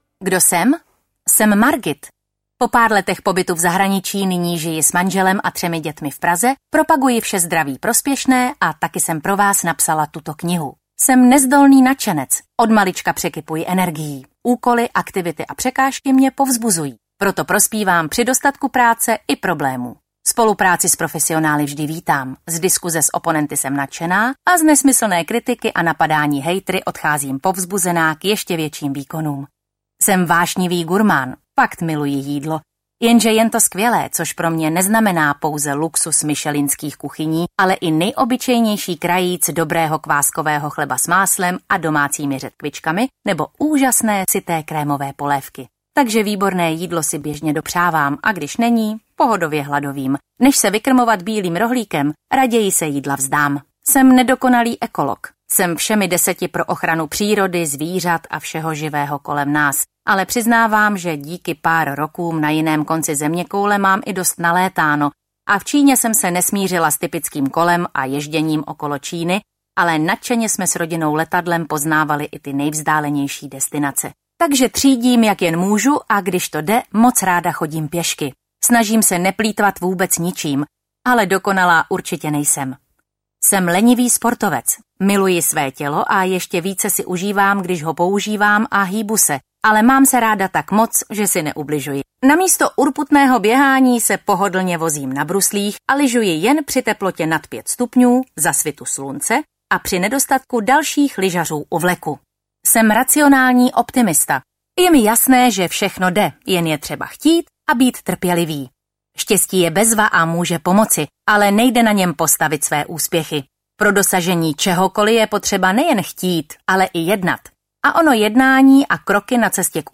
Velmi osobní kniha o zdraví audiokniha
Ukázka z knihy